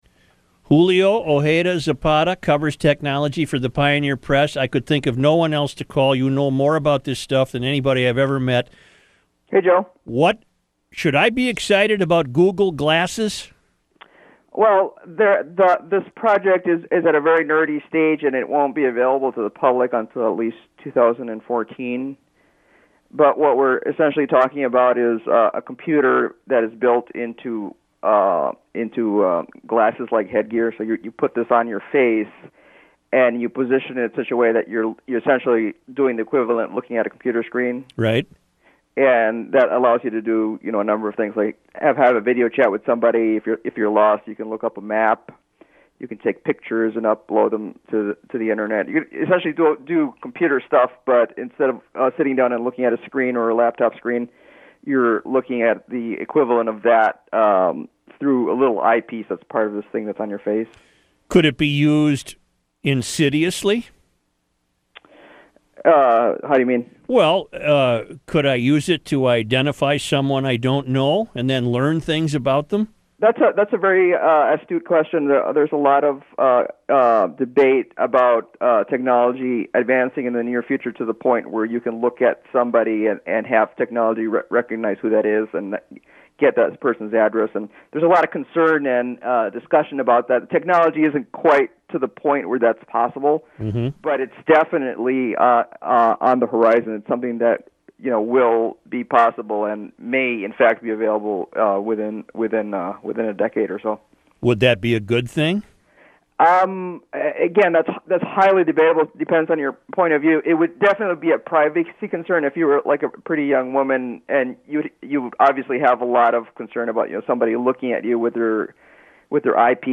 I talk BlackBerry on WCCO radio